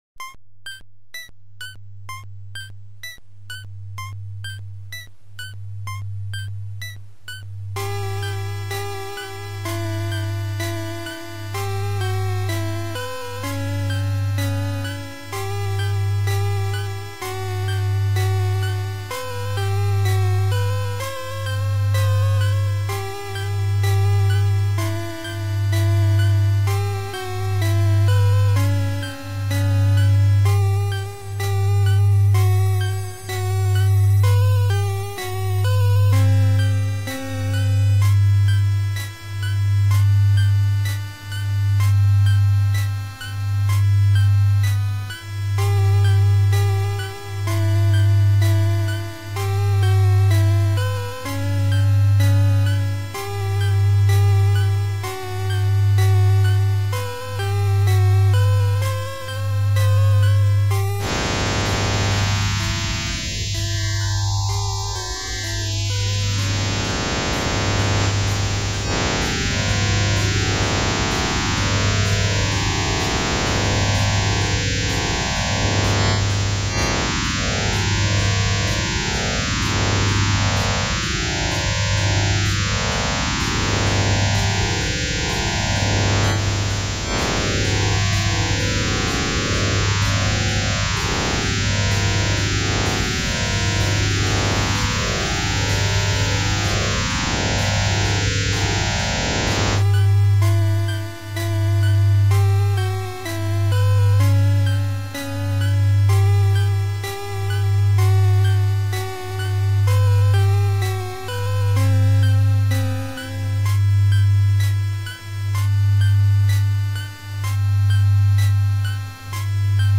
melodia fantasmagórica